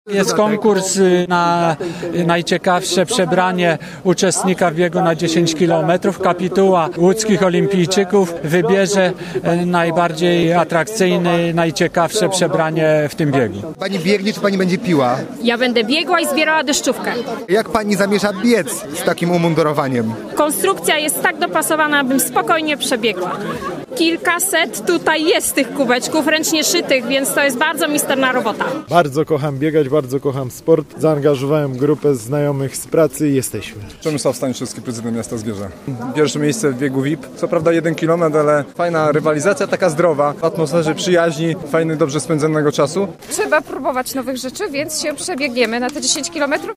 Posłuchaj relacji: Nazwa Plik Autor Sportowa rywalizacja i konkurs na najciekawsze przebranie podczas 37.